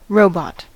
robot: Wikimedia Commons US English Pronunciations
En-us-robot.WAV